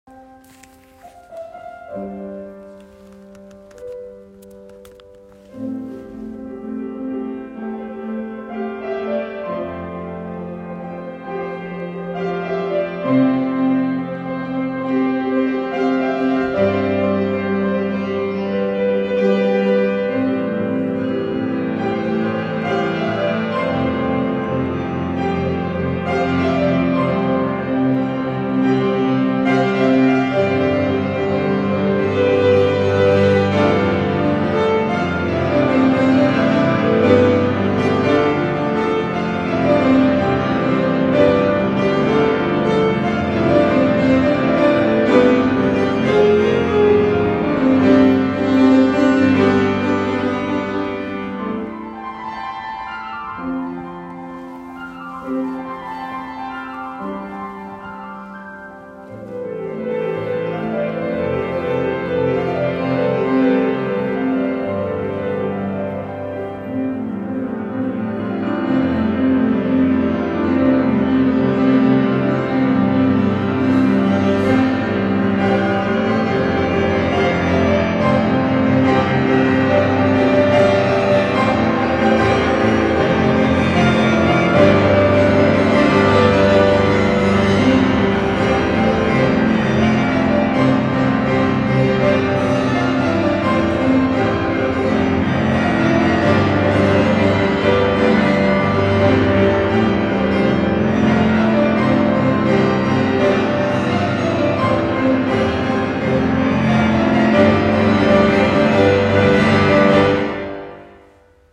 Gli spettacoli sono eseguiti dal vivo da musicisti professionisti, in luoghi suggestivi e sono già più di novanta le città al mondo in cui è arrivato Candlelight.
Le dita accarezzano i tasti, si muovono leggere e dolci e poi più decise e imperanti.
La platea è in silenzio e al buio.
Colonna-sonora-Star-Wars-piano.mp3